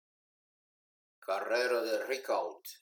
Prononcer "La Carrère", "La Carrèro"...